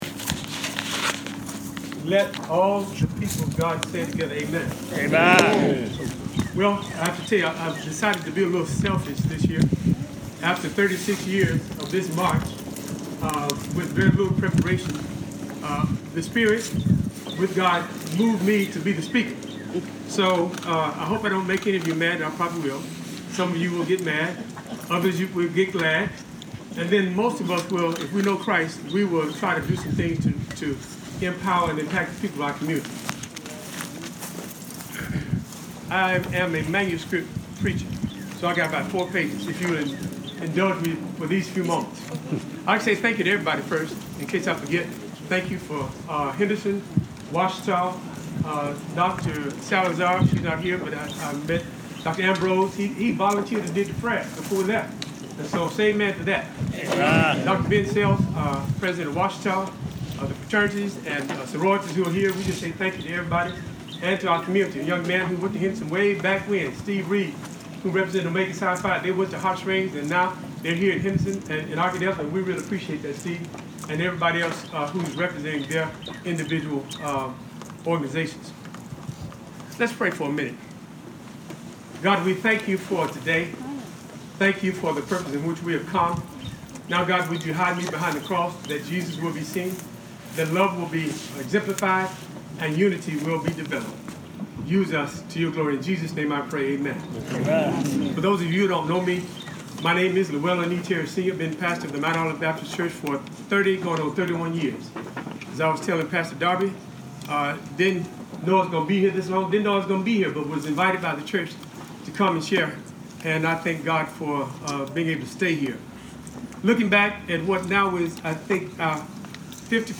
Recruit and include, urges pastor in MLK Day speech -